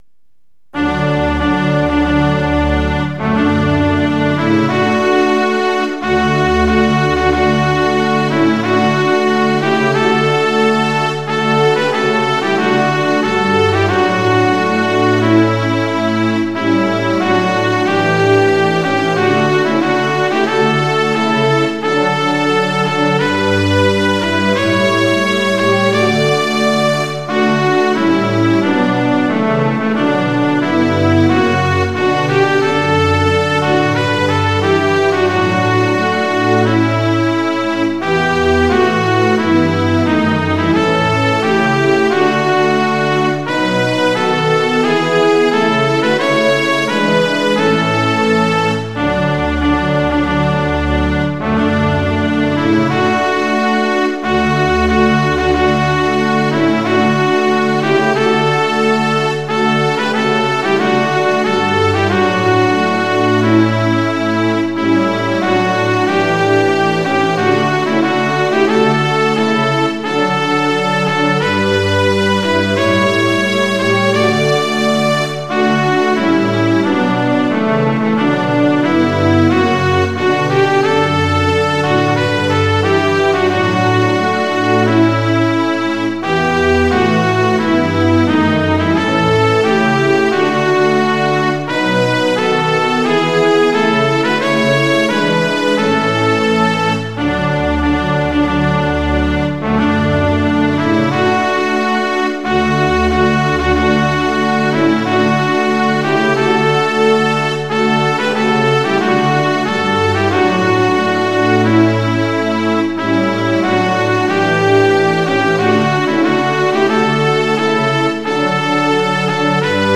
◆　４分の２拍子：　１拍目から始まります。